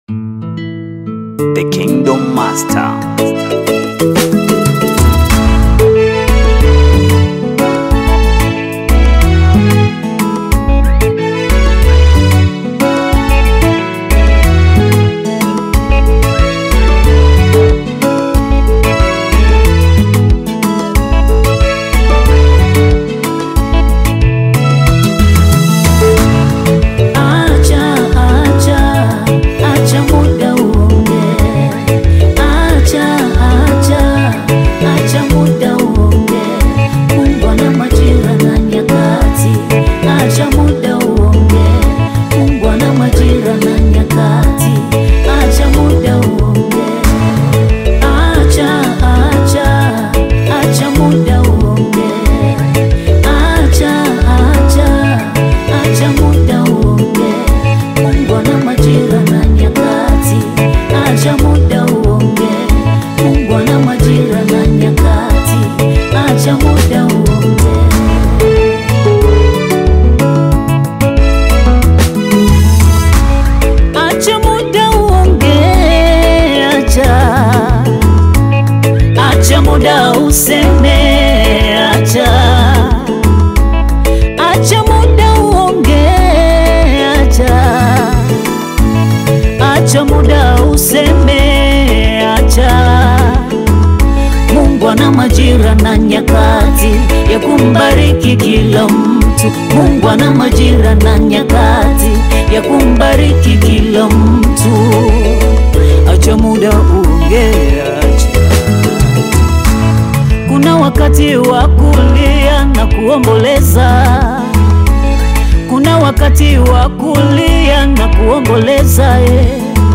Tanzanian gospel singer and songwriter
gospel song
African Music